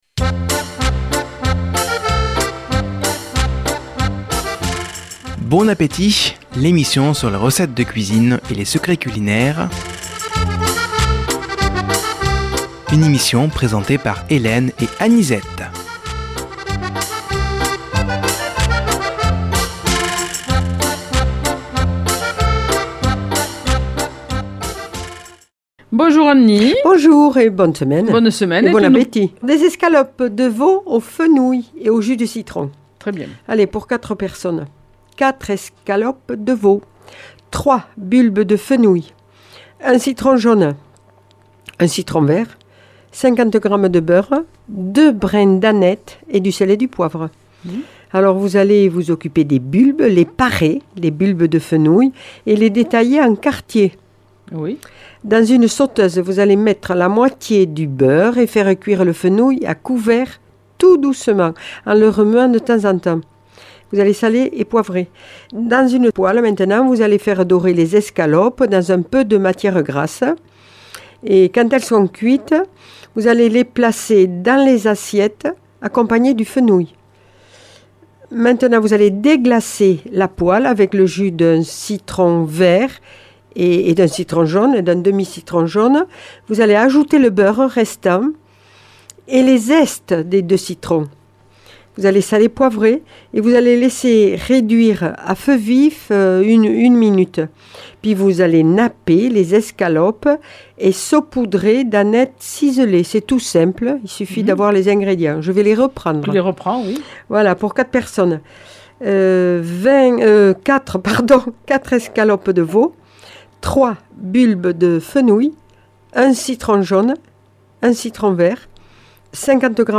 Une émission présentée par
Présentatrices